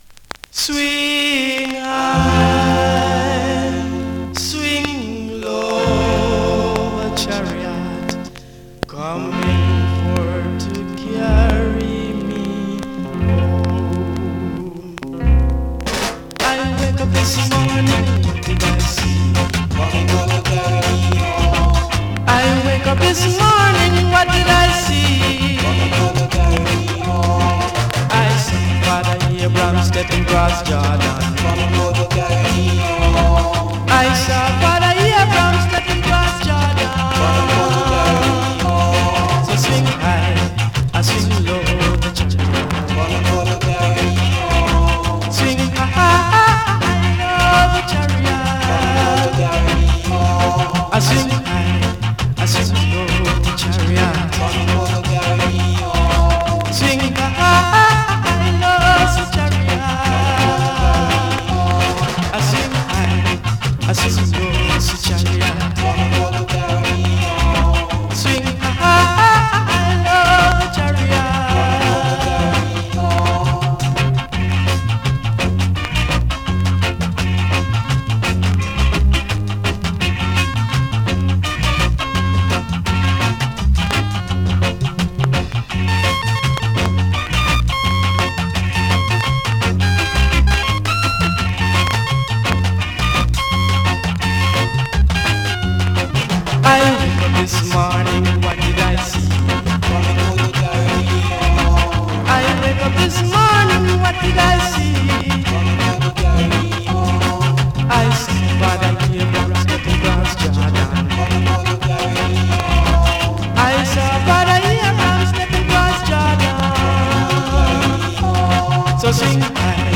2026!! NEW IN!SKA〜REGGAE
スリキズ、ノイズそこそこありますが